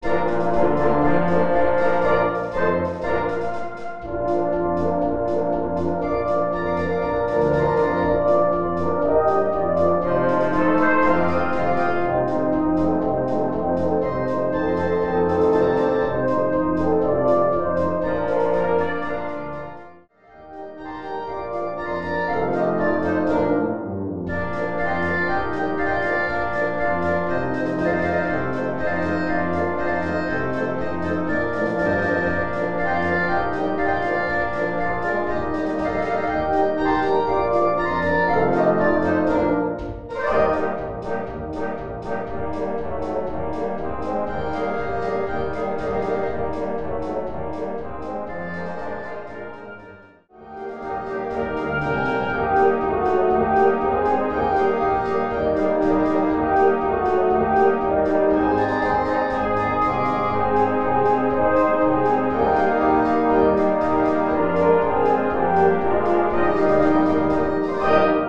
Polka mit Text im Trio